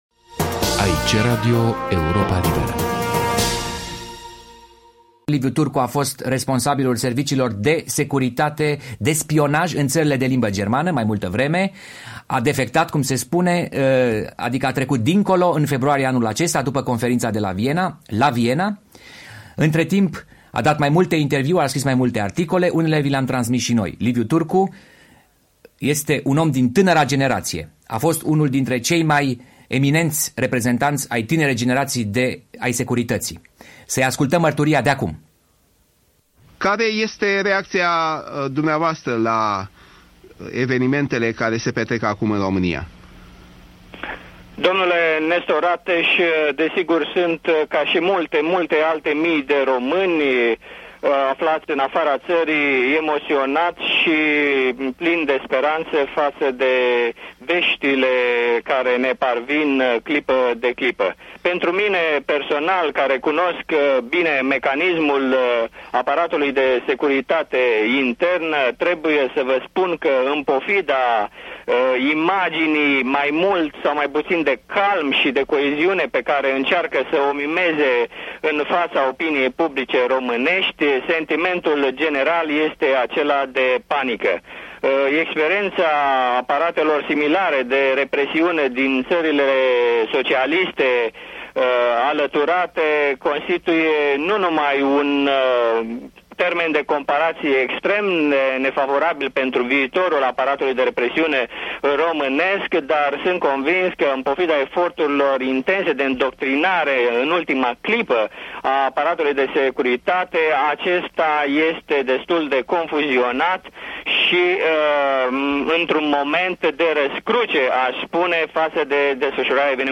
Un interviu difuzat în cadrul Studioului special al Europei Libere din 22 decembrie 1989, înainte de fuga lui Nicolae Ceaușescu.